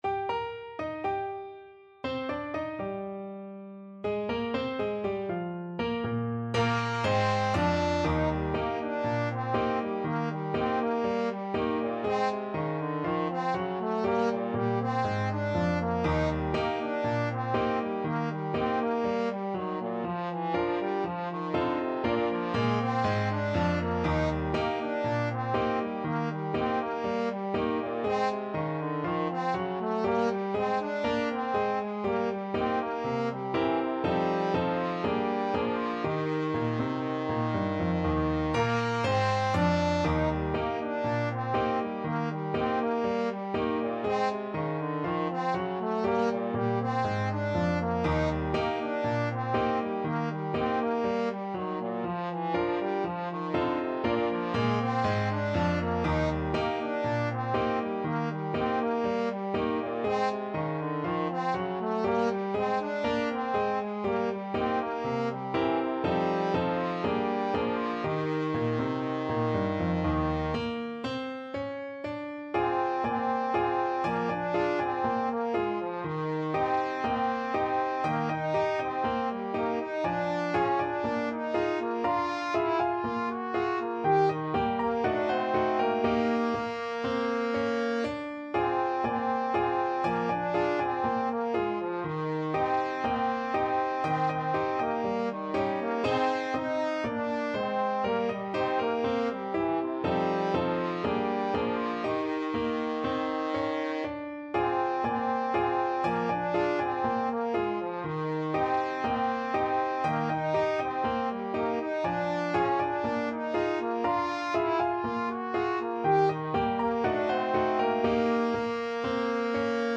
2/4 (View more 2/4 Music)
~ = 60 INTRO Not fast
Ab3-Ab5
Classical (View more Classical Trombone Music)